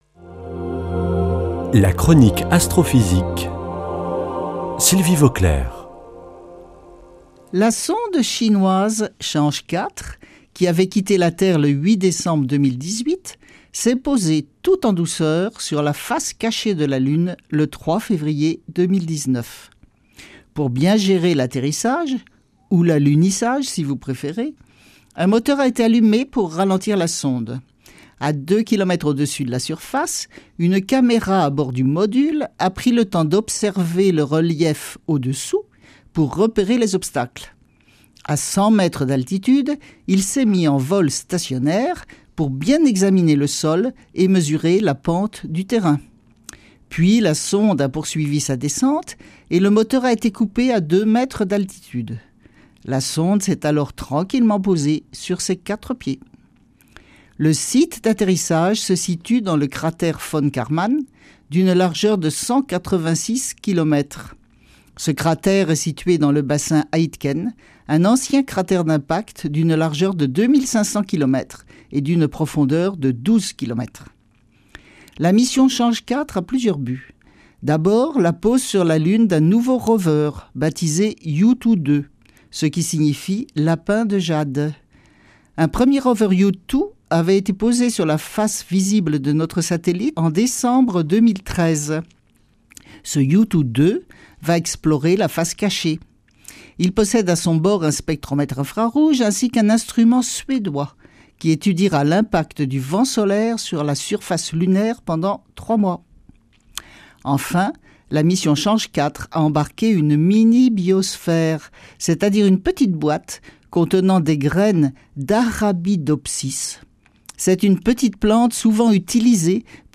lundi 11 février 2019 Chronique Astrophysique Durée 3 min
Astrophysicienne